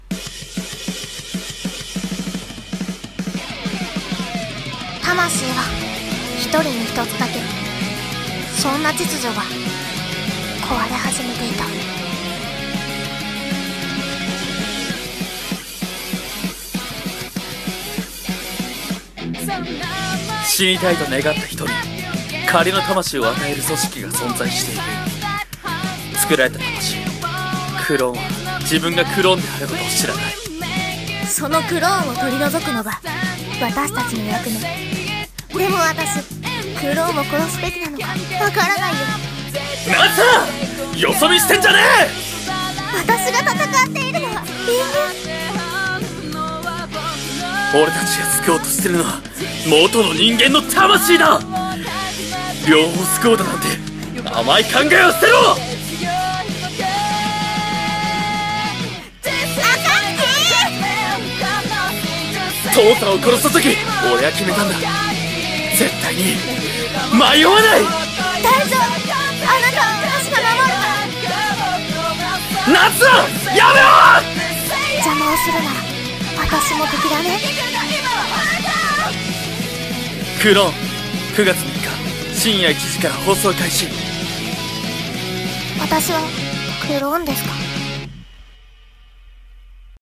【アニメ告知CM風声劇台本】CLONE【２人声劇】